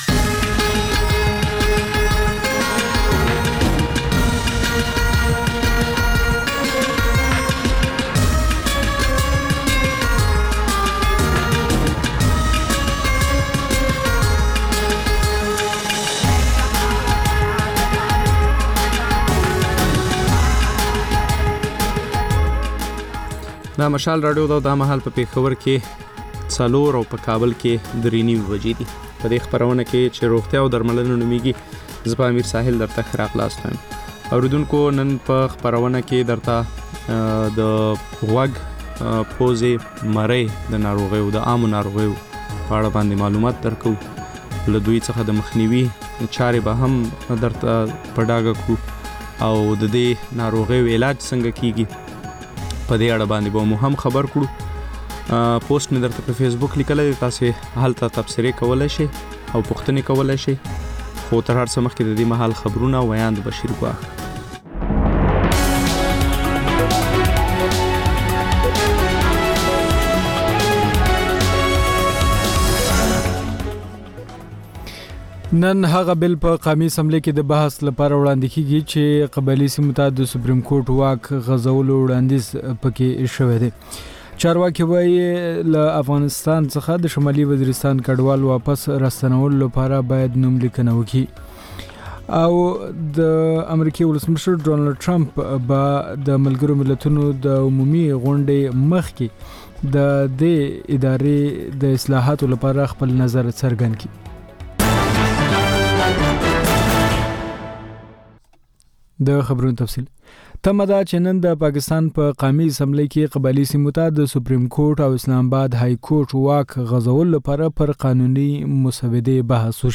د مشال راډیو مازیګرنۍ خپرونه. د خپرونې پیل له خبرونو کېږي. د دوشنبې یا د ګل پر ورځ د روغتیا په اړه ژوندۍ خپرونه روغتیا او درملنه خپرېږي چې په کې یو ډاکتر د یوې ځانګړې ناروغۍ په اړه د خلکو پوښتنو ته د ټیلي فون له لارې ځواب وايي.